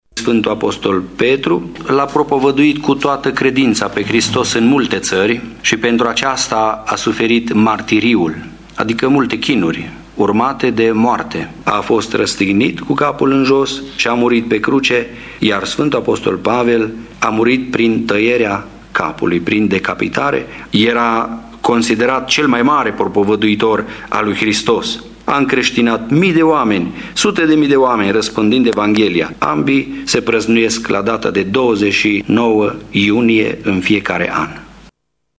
Preotul ortodox